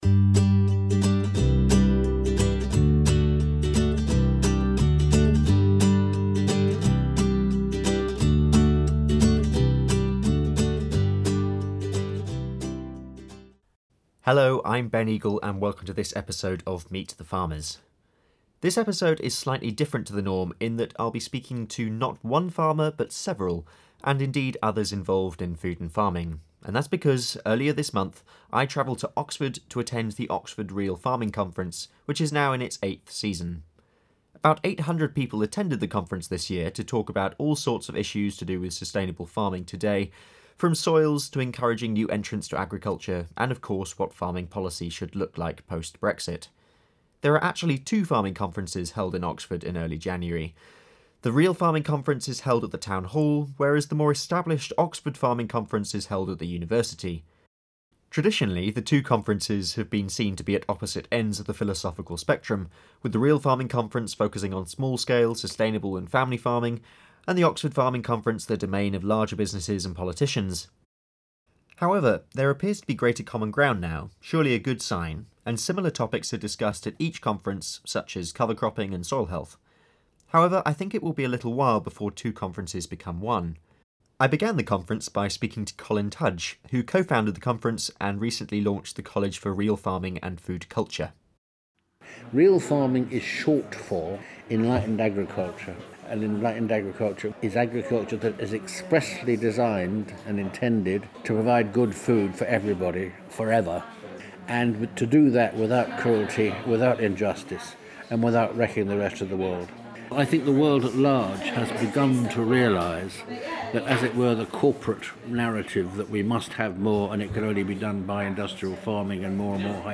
For this episode of Meet the Farmers I travelled to the Oxford Real Farming Conference, which took place from 4th-5th January, and I spoke to farmers and others about the future of food and farming in the UK.